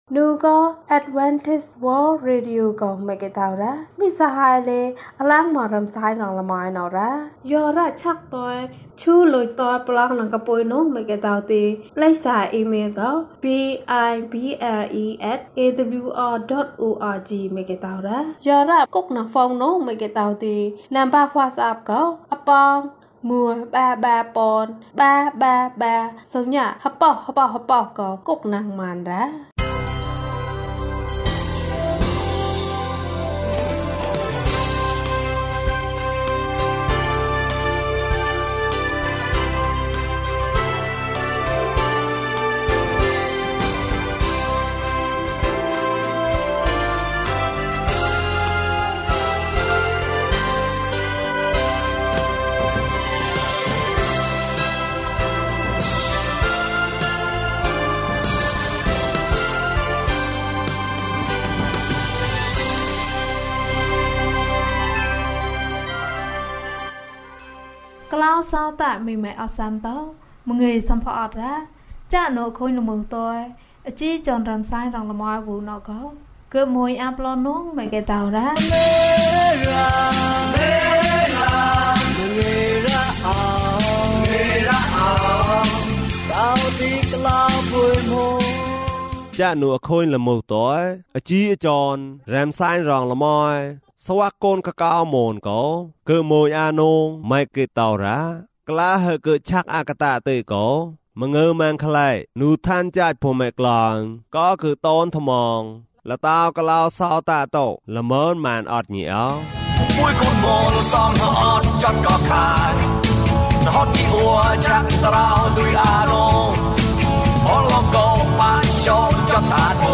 ခရစ်တော်ထံသို့ ခြေလှမ်း။၀၈ ကျန်းမာခြင်းအကြောင်းအရာ။ ဓမ္မသီချင်း။ တရားဒေသနာ။